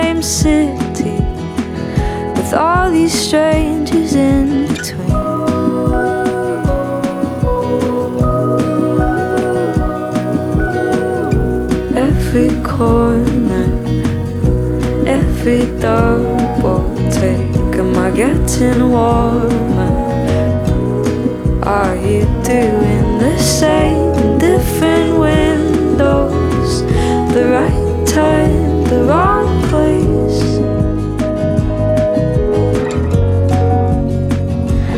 Жанр: Альтернатива